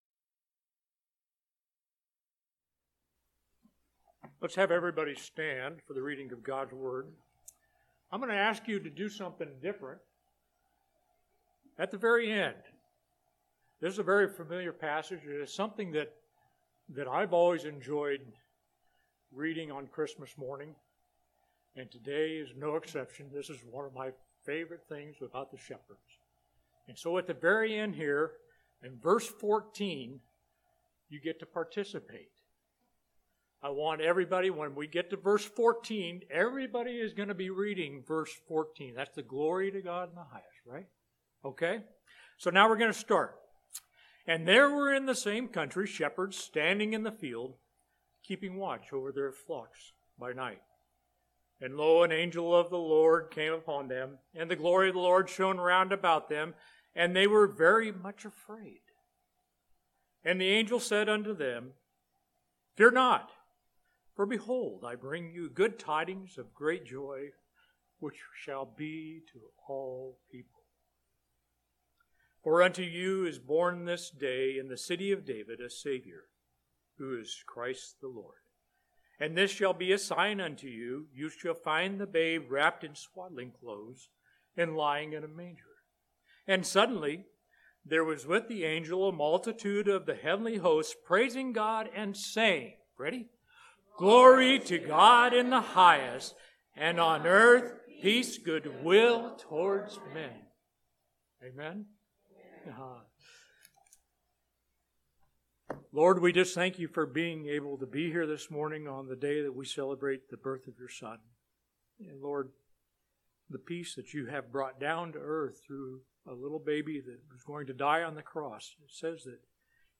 Special Sermon Passage: Luke 2:8-14